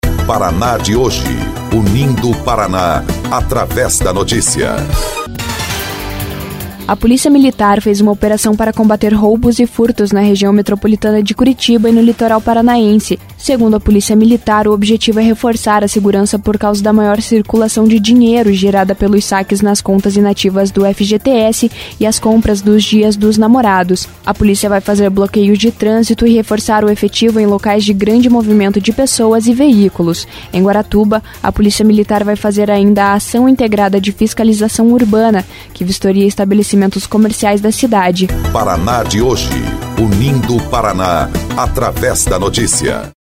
12.06 – BOLETIM – Polícia Militar faz operação no Paraná para combater crimes contra o FGTS